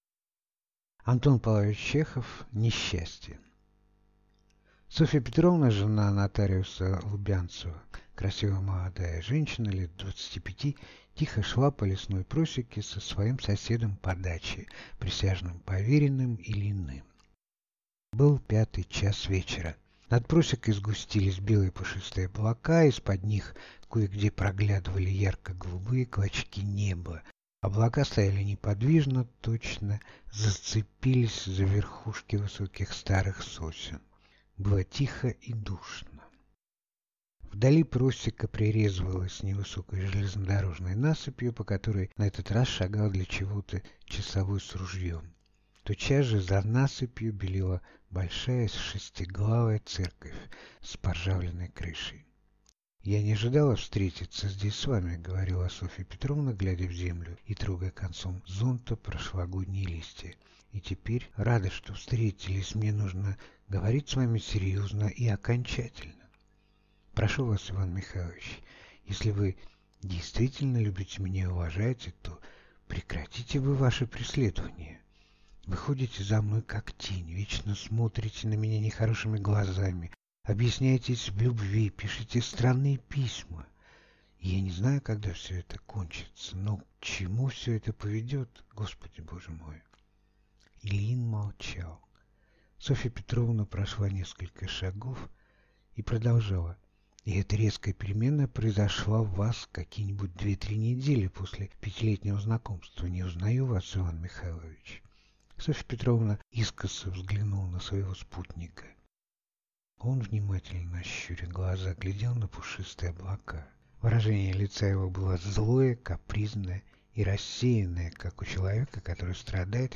Аудиокнига Несчастье | Библиотека аудиокниг